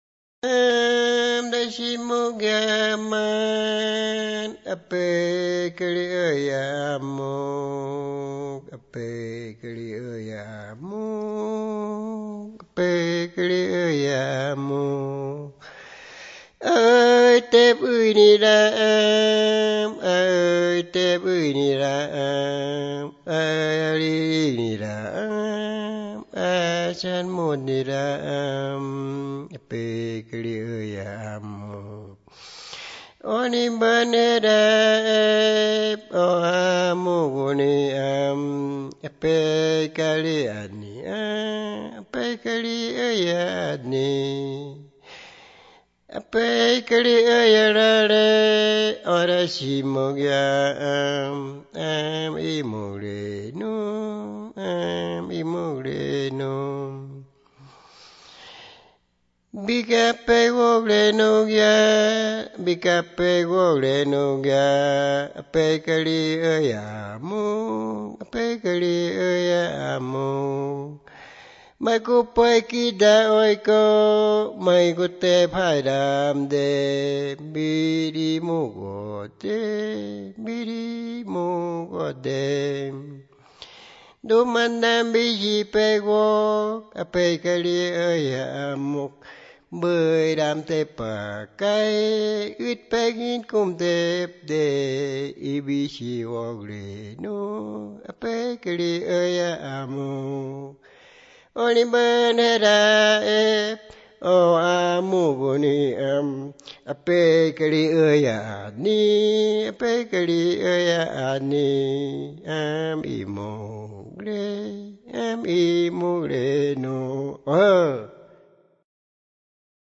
Piedra Alta, Medio Inírida, Guainía (Colombia)
The singer says to his brother-in-law, "I say, I who have no owner... rejoice, now that we are drinking, 'water' is what has been fermenting, what we are going to drink..." The recording, transcription, and translation of the song took place between March and April 2001 in Piedra Alta; a subsequent studio recording was made in Bogotá in 2004.
Canciones Wãnsöjöt